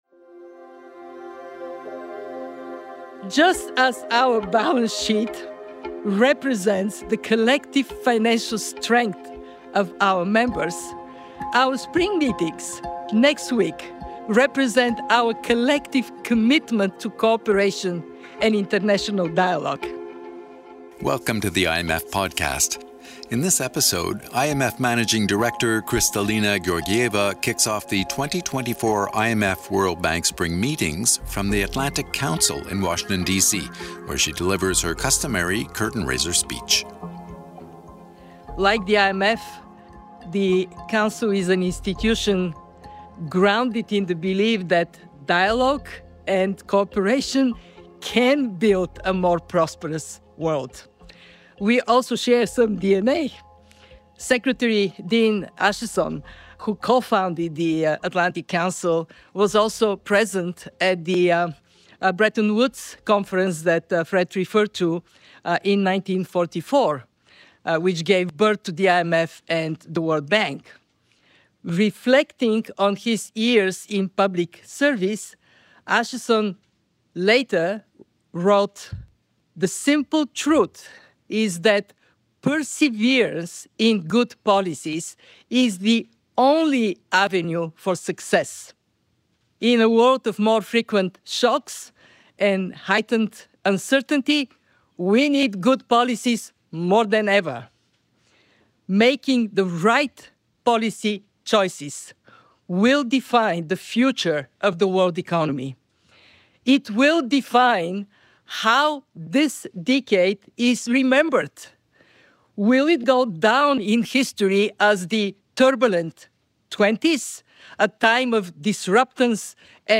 IMF Managing Director Kristalina Georgieva kicks off the 2024 IMF-World Bank Spring Meetings from the Atlantic Council in Washington, DC, with her customary curtain raiser speech.